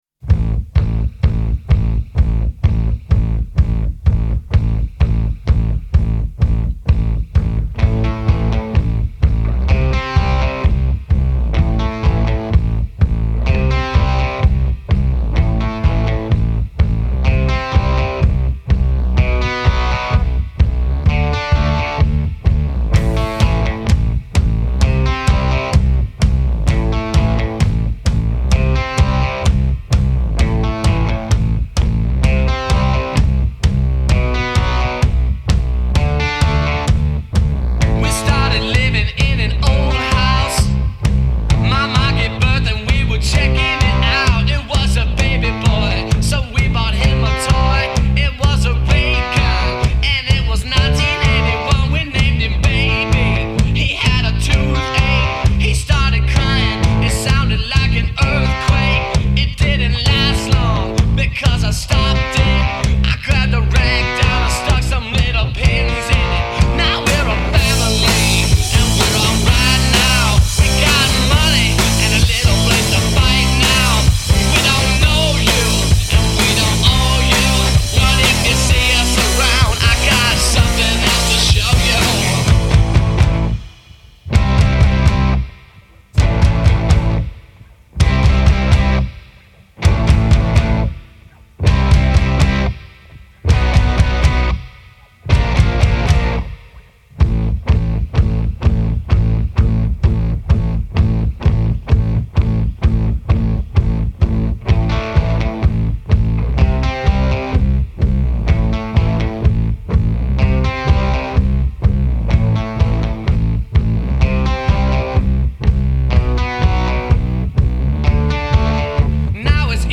Género: Rock.